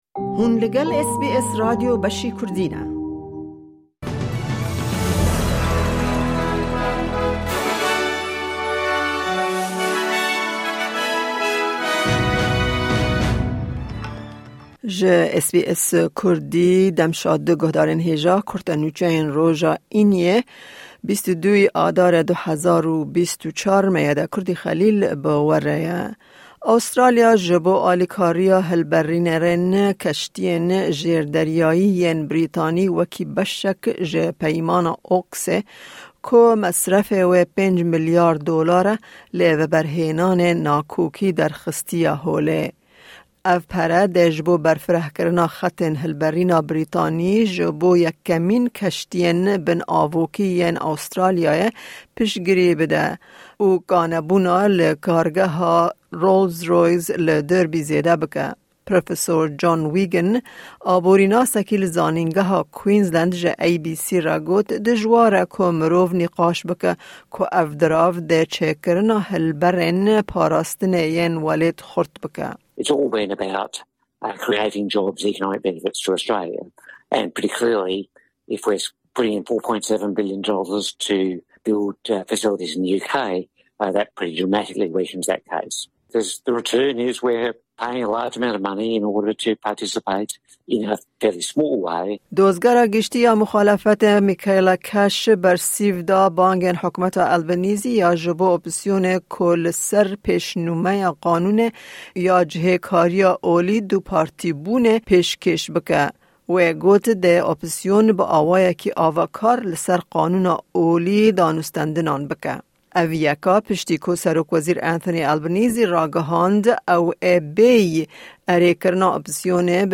Kurte Nûçeyên roja Înê 22î Adara 2024